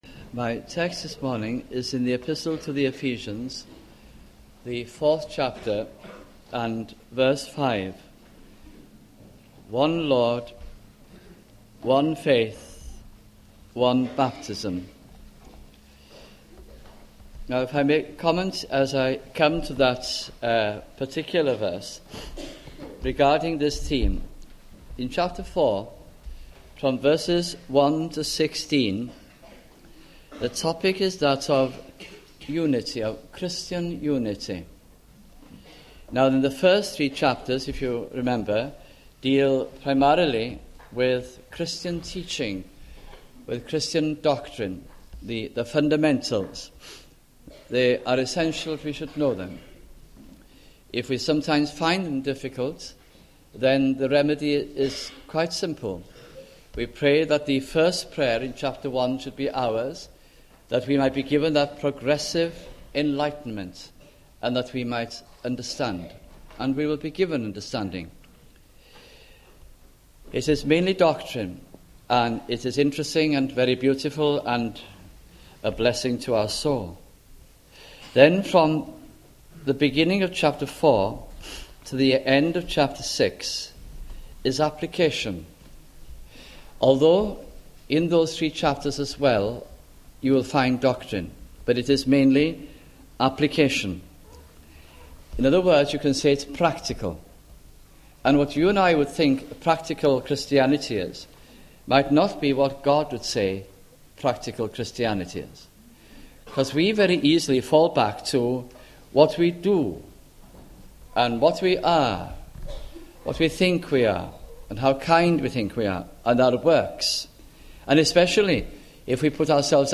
» Ephesians Series 1991 » sunday morning messages